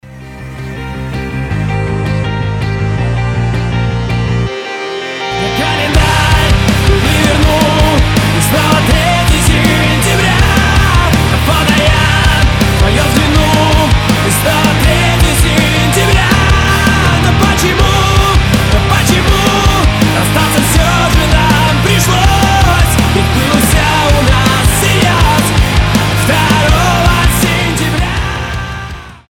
громкие
Cover
nu metal
Alternative Rock
Mashup